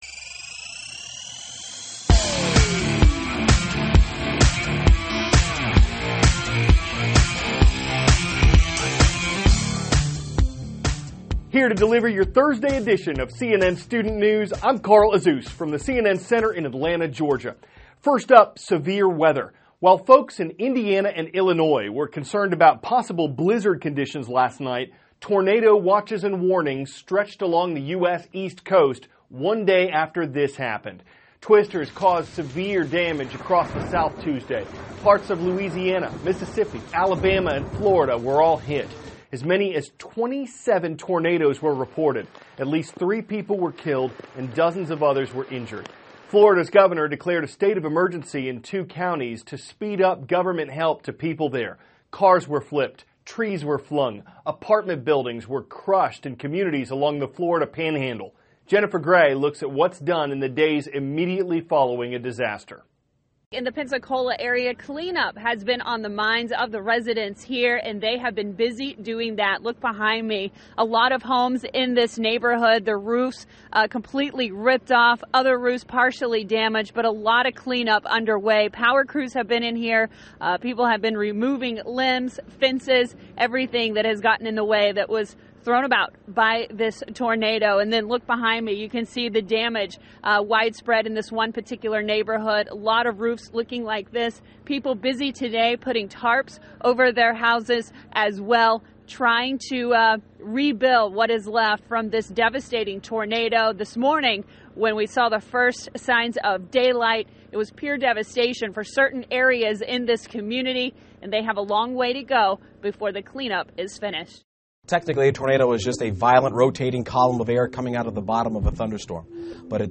(CNN Student News) -- February 25, 2016 Parts of U.S. Recover from Severe Weather; A Look at Life After A National Campaign THIS IS A RUSH TRANSCRIPT.